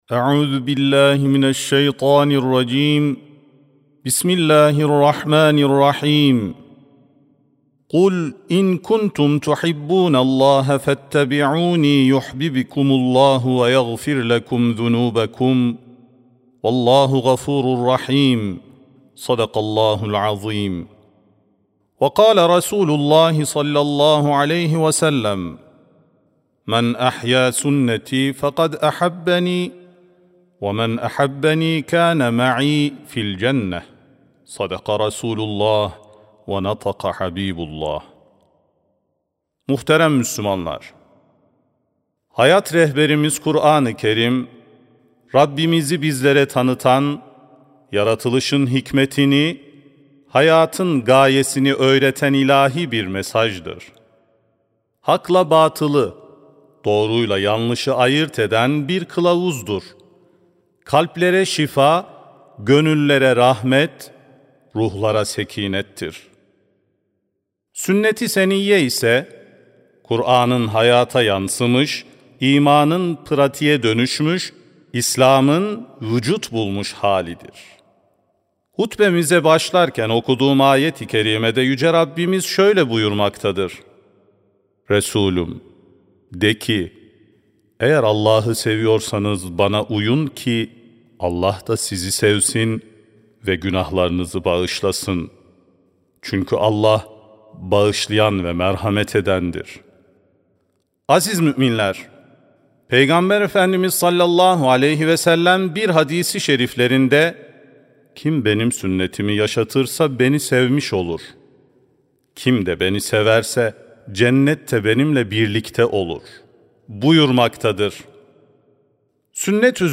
17.10.2025 Cuma Hutbesi: Müslümanın Hayatına Yön Veren Rehber: Sünnet-i Seniyye (Sesli Hutbe, Türkçe, İngilizce, Rusça, Arapça, Almanca, İtalyanca, İspanyolca, Fransızca)
Sesli Hutbe (Müslümanın Hayatına Yön Veren Rehber, Sünnet-i Seniyye).mp3